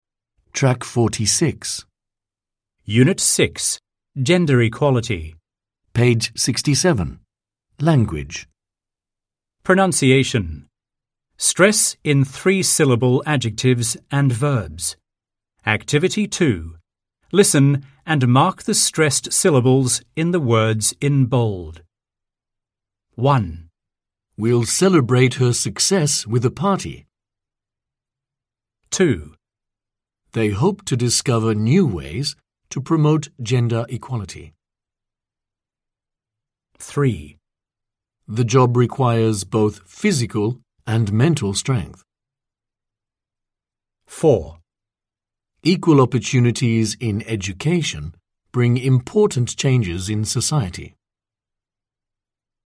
2. Listen and mark the stressed syllable in the word in bold.
1. ˈcelebrate 2. disˈcover 3. ˈphysical 4. imˈportant